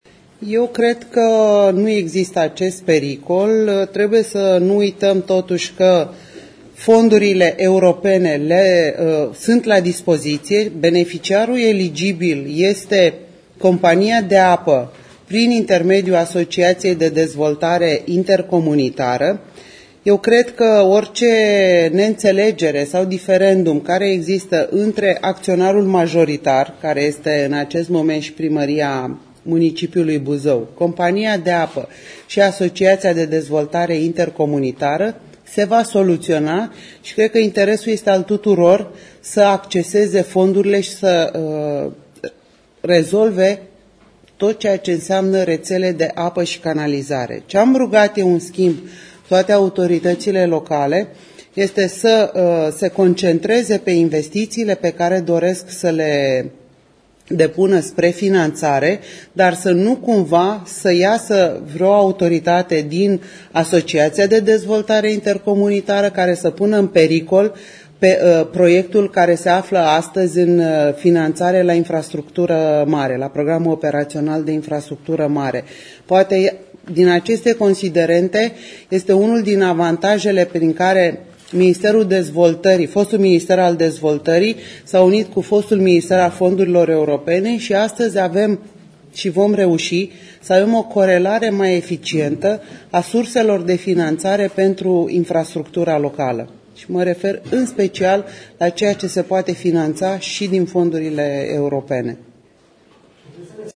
Neînțelegerile dintre Primăria municipiului Buzău, Compania de Apă și Asociația de Dezvoltare Intercomunitară „Buzău 2008” trebuie să înceteze și toată lumea trebuie să se concentreze pe atragerea fondurilor europene. A spus-o clar vicepremierul Sevil Shhaideh la vizita desfășurată sâmbătă la Buzău, unde a luat act de neînțelegerile existente în plan local.